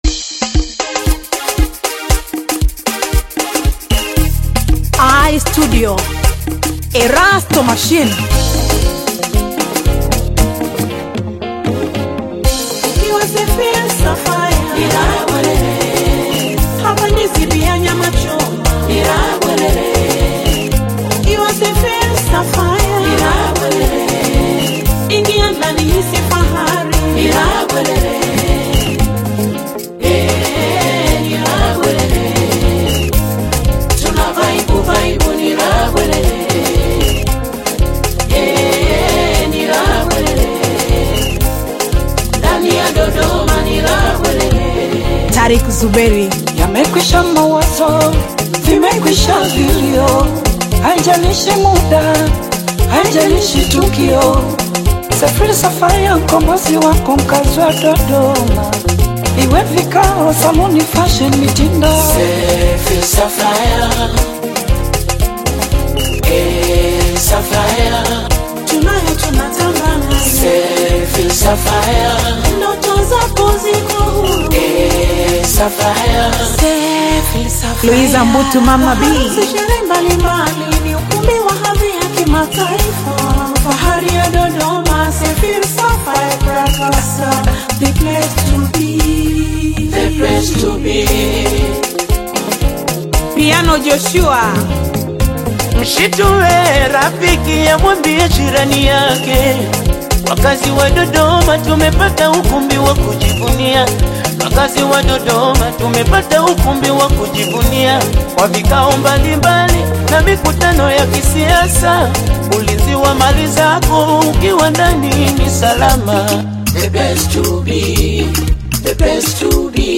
AudioRhumba
a fresh electronic-focused single